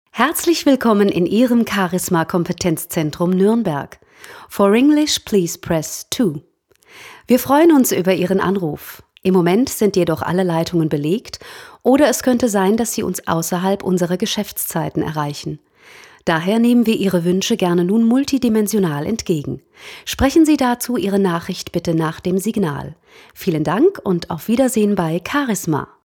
frische, dynamische Erwachsenenstimme
Sprechprobe: Werbung (Muttersprache):